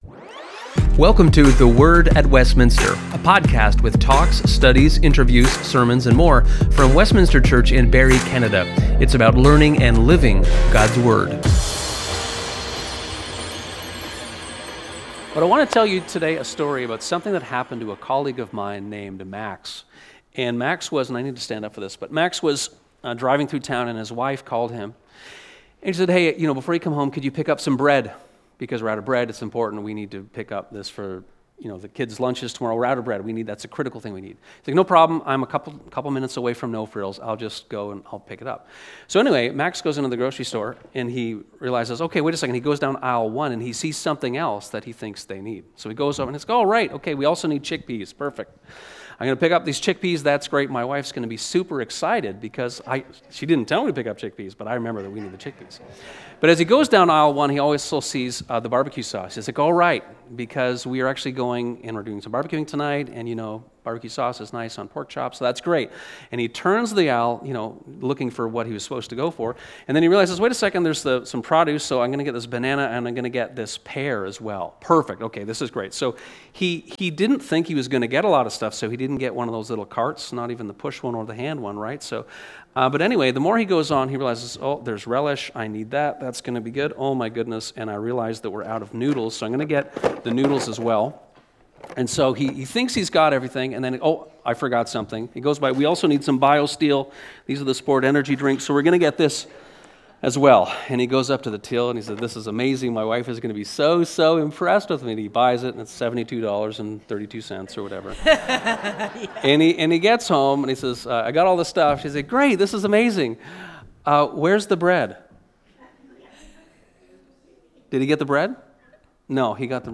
This short episode is the youth focus from the service on October 19, 2025.